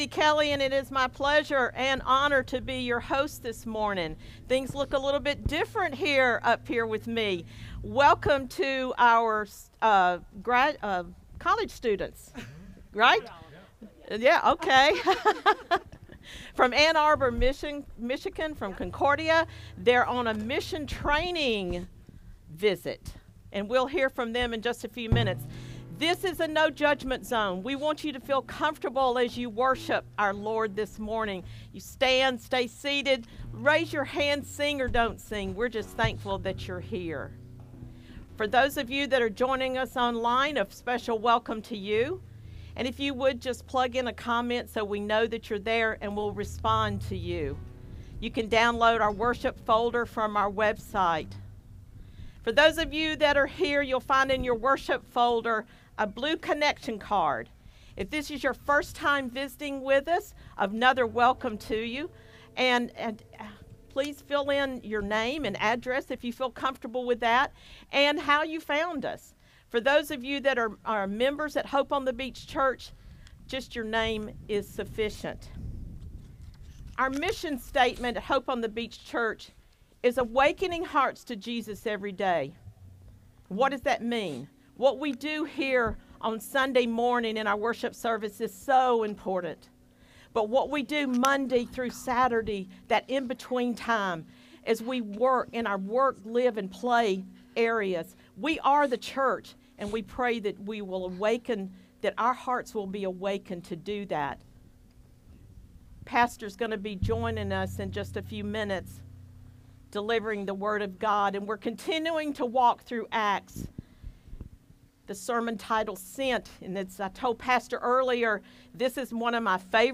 SERMON DESCRIPTION It is all about serving and dying to self!